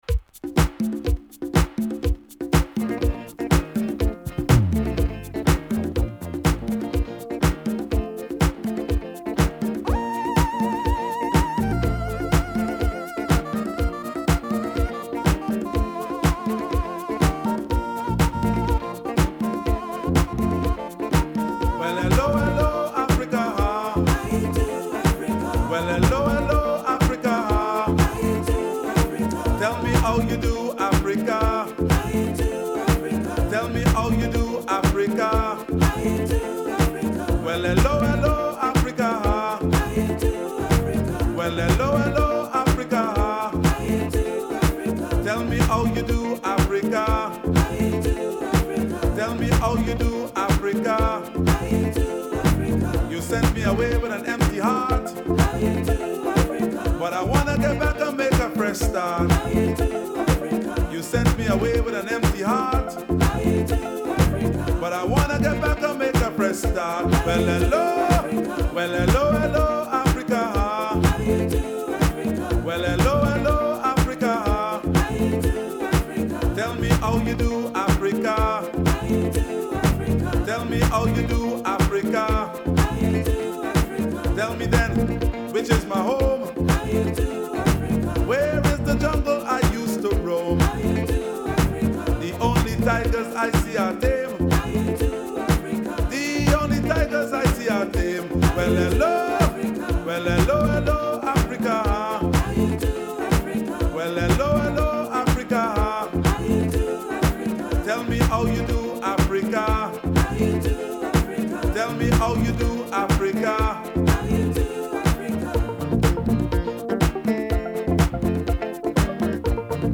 陽気で爽快なアフロ・ディスコ！